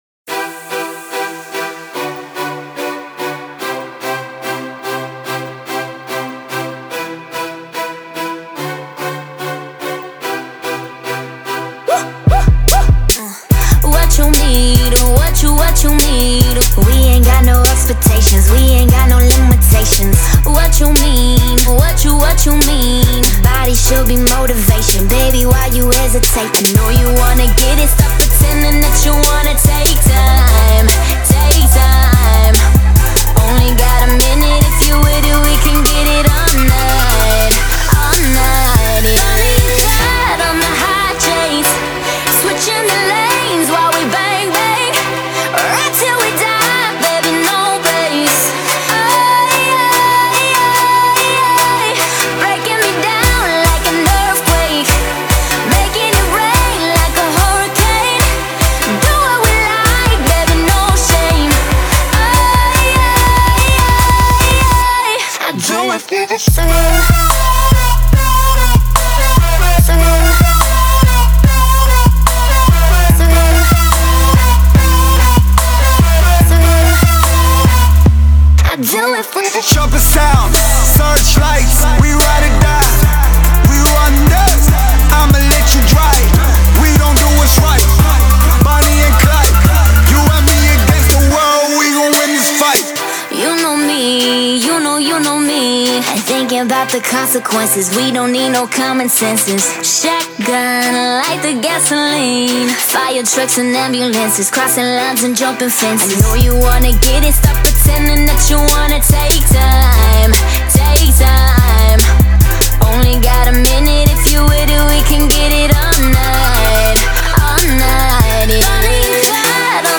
• Жанр: Electronic, EDM, House